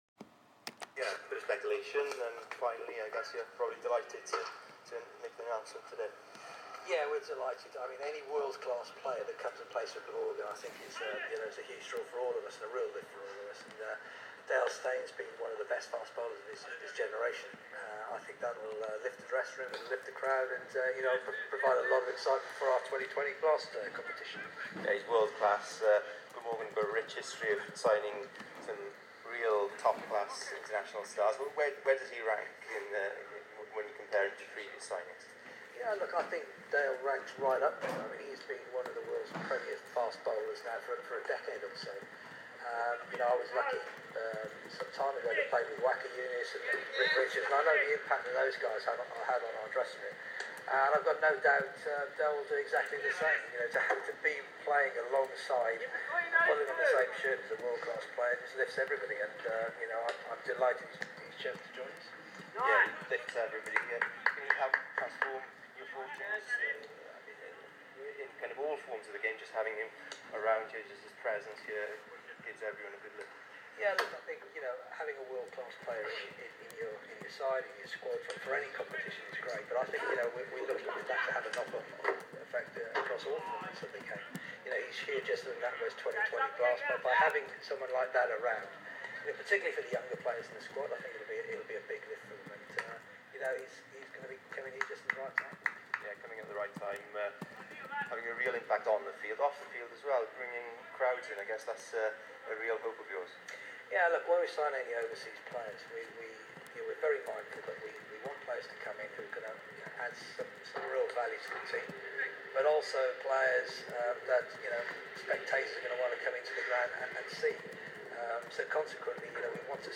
Glamorgan Chief Exec talks to the media after the Dale Steyn announcement